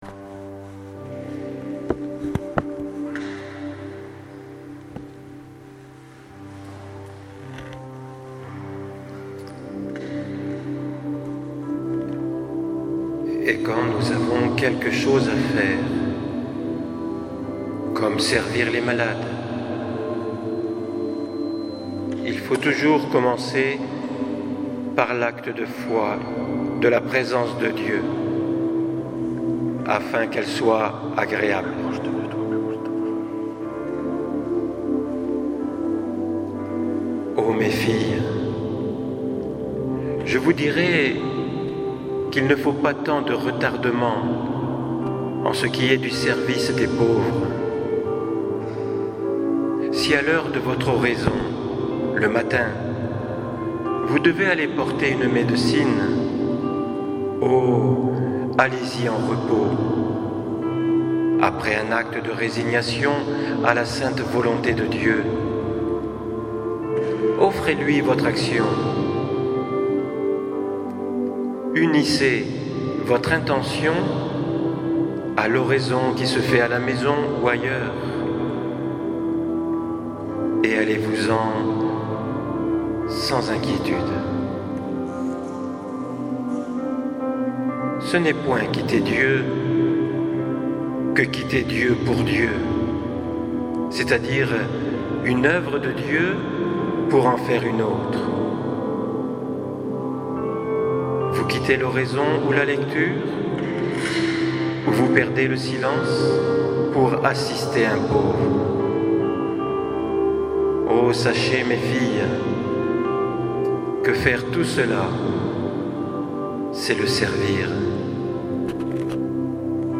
le samedi soir nous avons vécu une veillée de louange et adoration ponctuer de textes de st Vincent que vous pouvez découvrir ici en audio